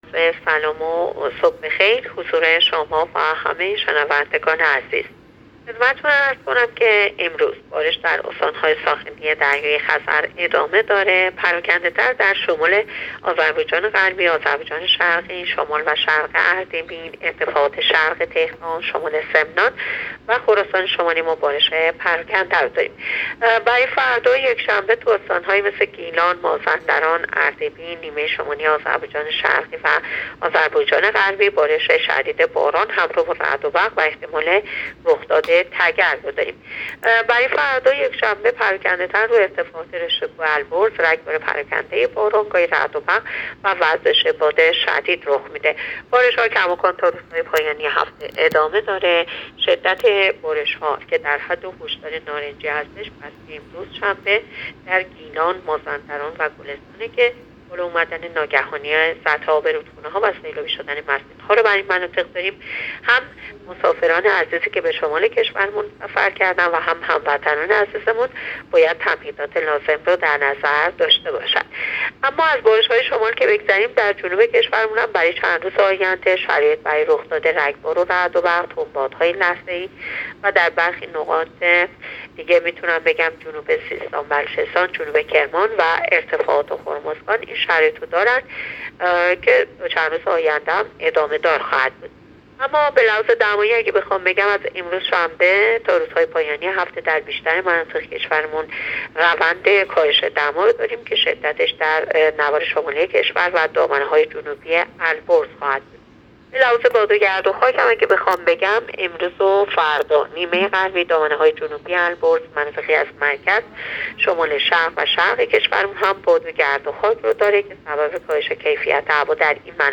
گزارش رادیو اینترنتی پایگاه‌ خبری از آخرین وضعیت آب‌وهوای ۲۹ شهریور؛